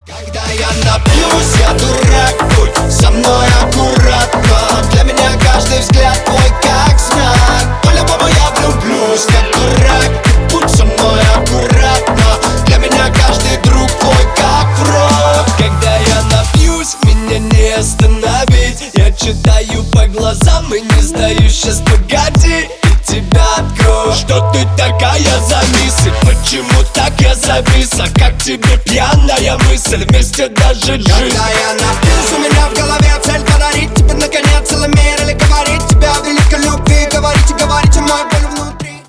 Ремикс # Танцевальные
громкие